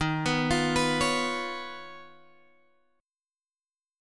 D# 7th Suspended 2nd Sharp 5th